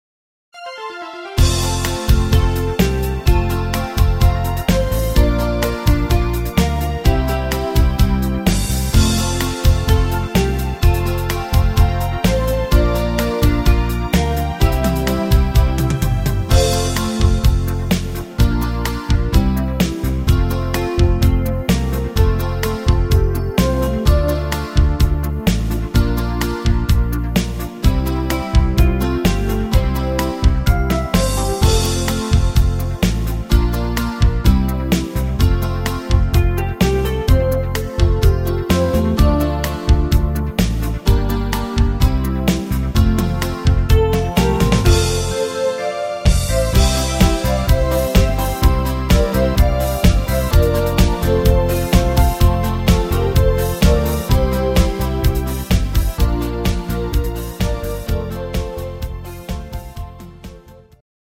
Rhythmus  Medium Beat
Art  Schlager 90er, Deutsch